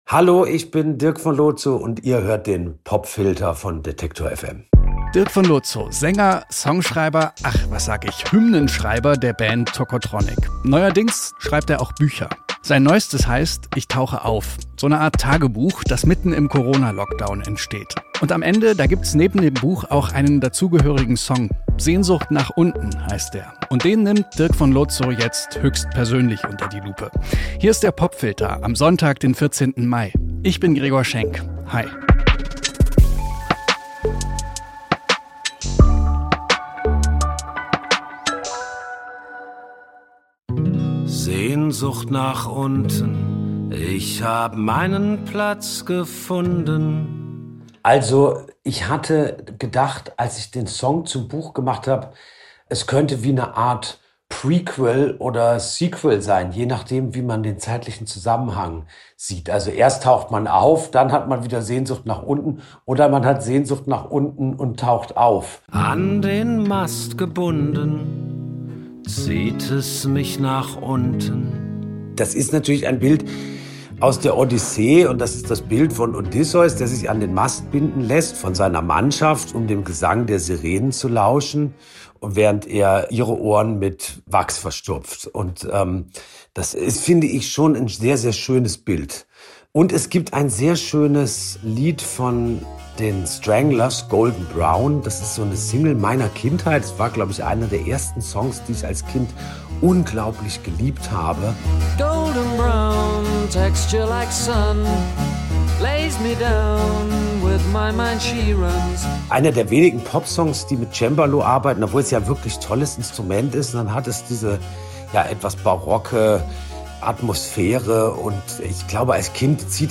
Statt den Sirenen gibt es hier aber feinfühlige Streicher und countryfizierte Gitarren. Der Tocotronic-Sänger ist im Popfilter zu Gast und nimmt den Song höchstpersönlich unter die Lupe.